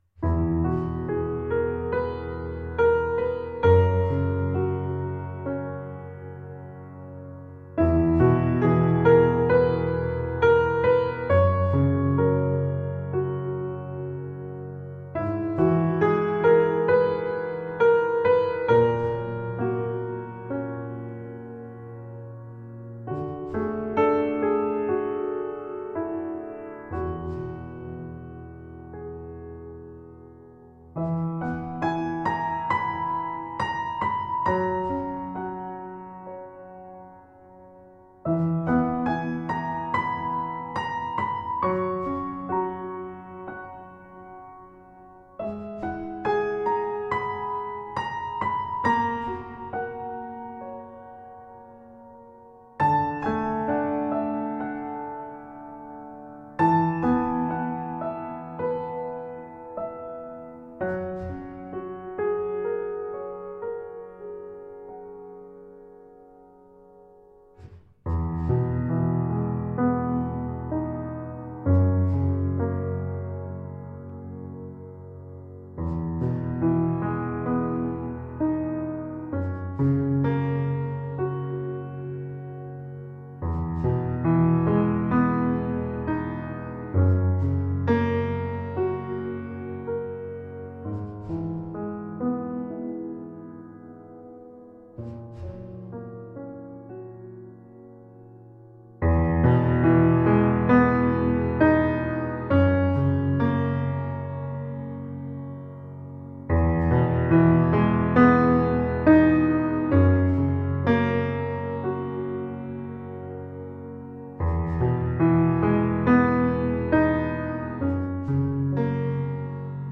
Emotional piano explorations.